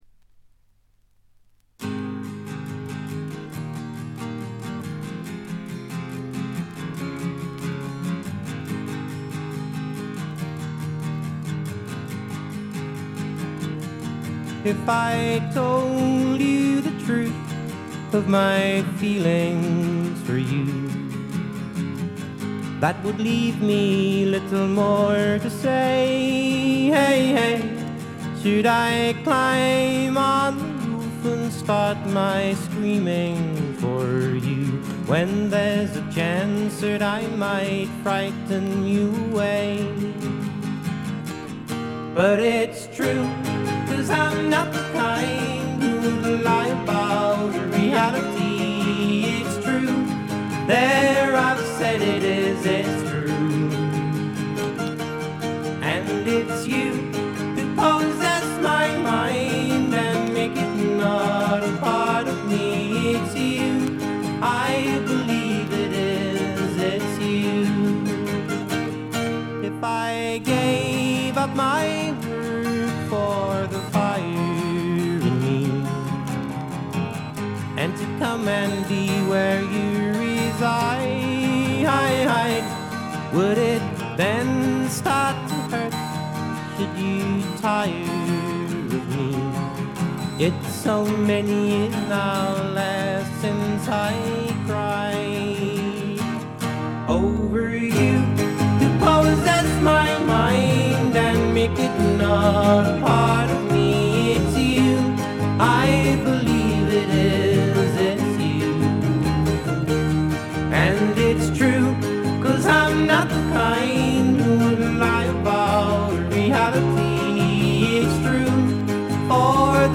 部分試聴ですが、軽微なノイズ感のみ。
やさしさにあふれた英国シンガー・ソングライターの好盤です。
試聴曲は現品からの取り込み音源です。
Recorded At - Morgan Studios